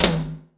ROOM TOM1B.wav